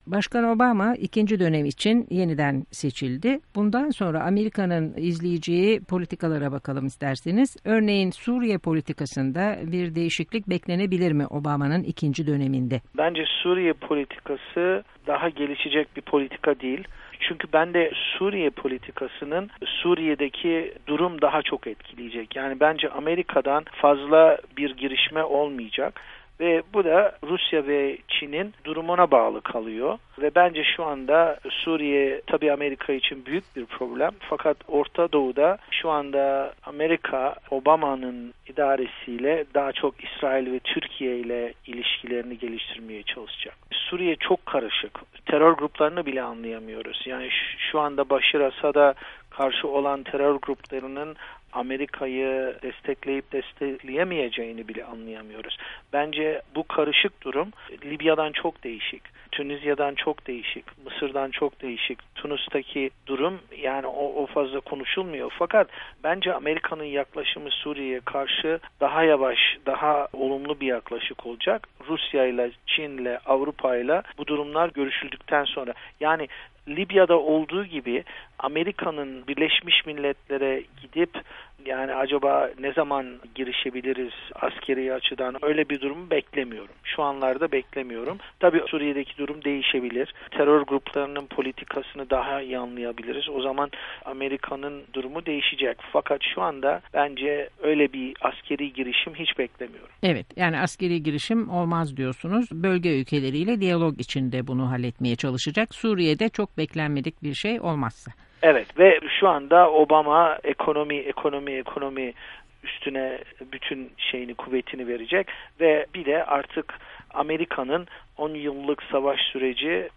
Söyleşi